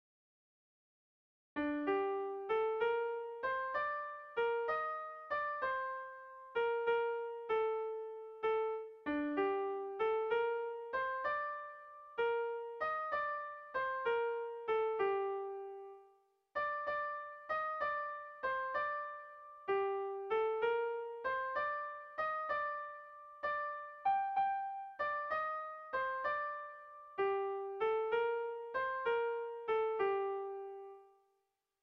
Melodías de bertsos - Ver ficha   Más información sobre esta sección
Irrizkoa
Zortziko txikia (hg) / Lau puntuko txikia (ip)
A1A2BD